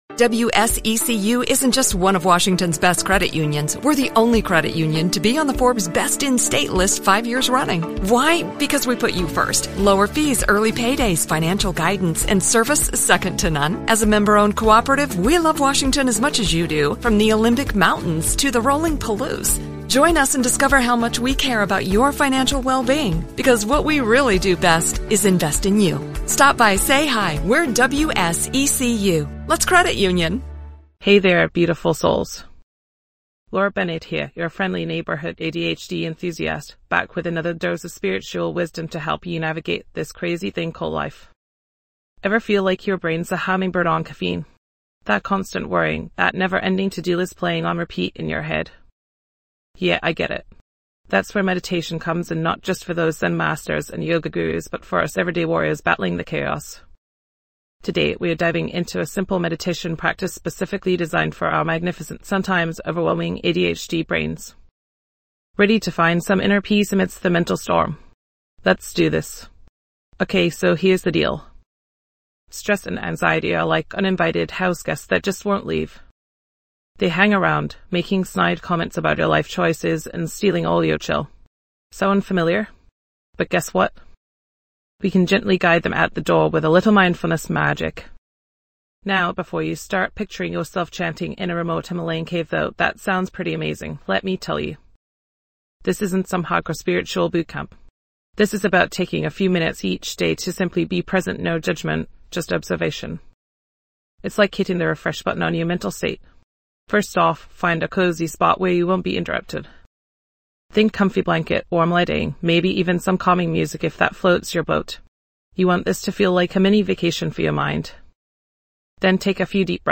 This episode of ADHD, But Make It Spiritual offers a soothing guided meditation session, helping you find inner peace and calmness, all while fostering spiritual growth.
This podcast is created with the help of advanced AI to deliver thoughtful affirmations and positive messages just for you.